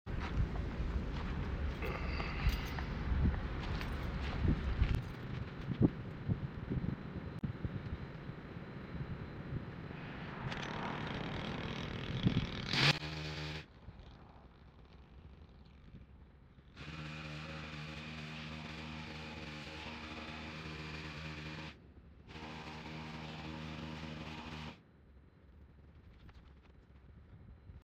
trimming mesquite trees pole saw sound effects free download
trimming mesquite trees pole saw at full extension in the summer heat